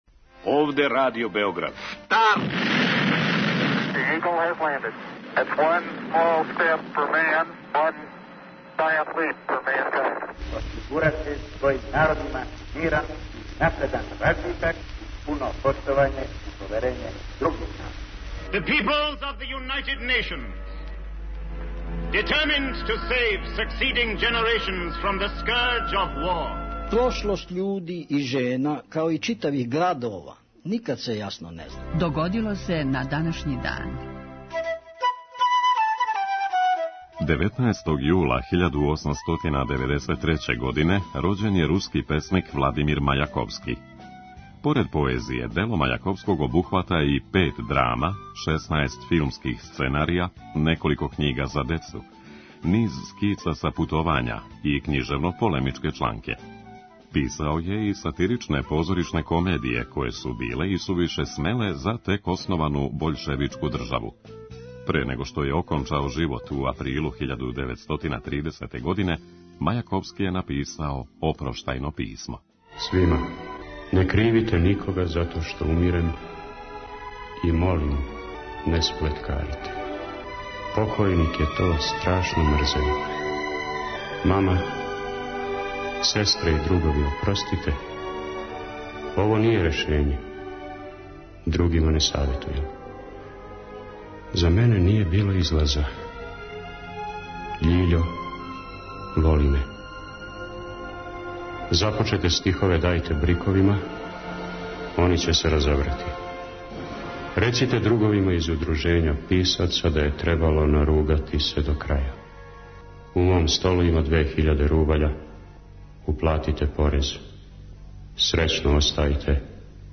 У петотоминутном прегледу, враћамо се у прошлост и слушамо гласове људи из других епоха.